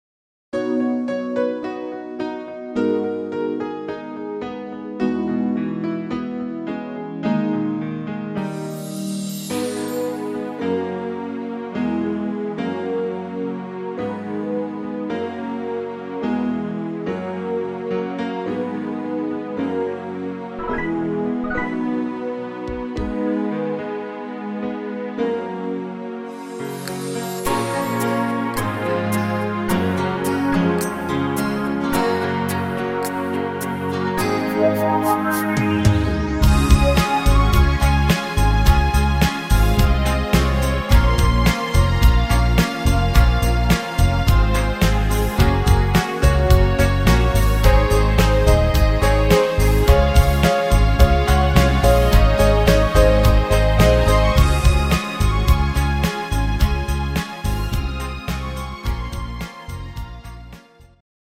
Rhythmus  Medium Beat
Art  Englisch, Pop